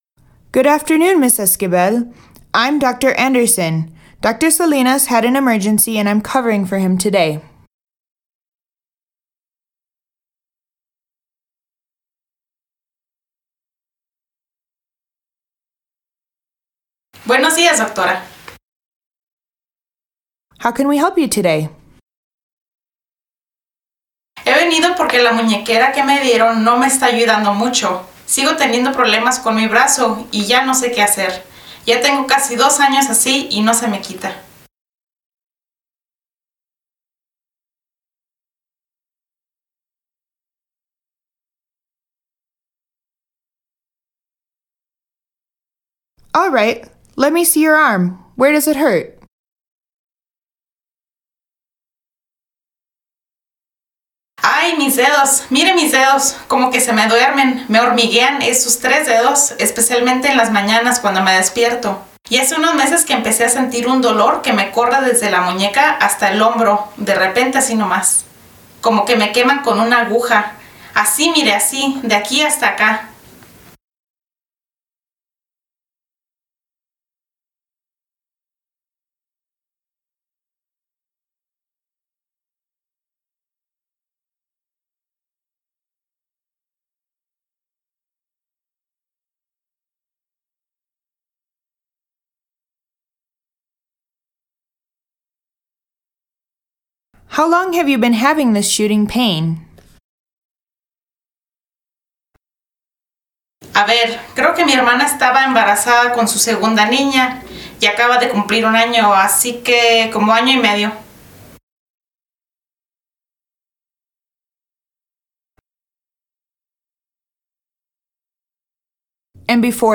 VCI-Practice-Dialogue-04-Carpal-Tunnel-EN-SP.mp3